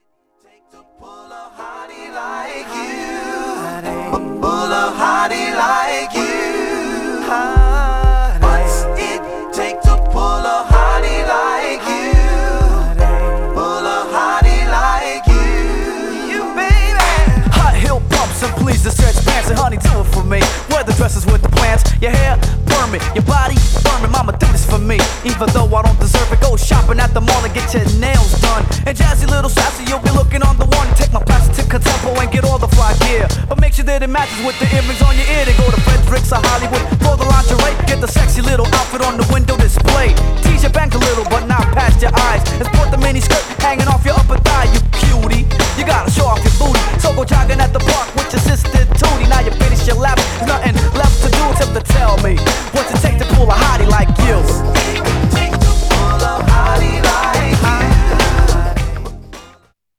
Styl: Hip Hop
Instrumental